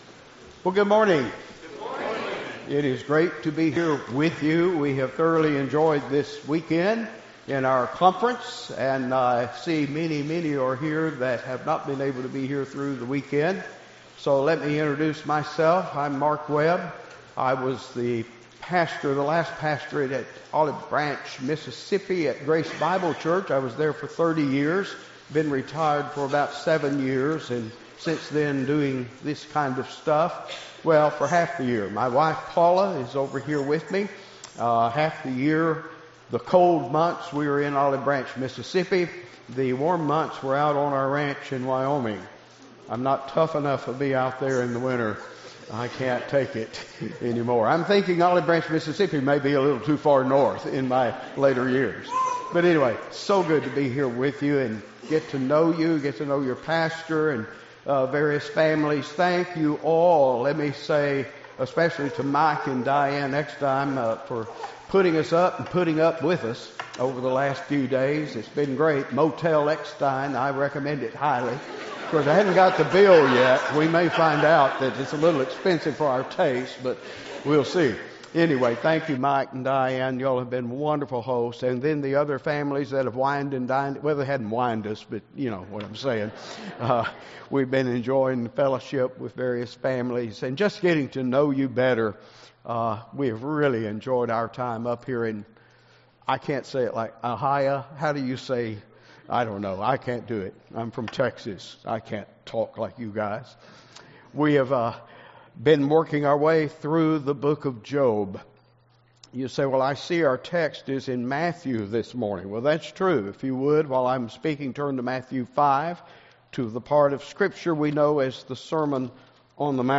Foundations of Grace Session 5 - Worship Service - "The Application of Job"